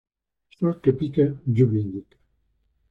Pronounced as (IPA) /ˈsol/